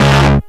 Grind Bass (WHAT_S GOOD).wav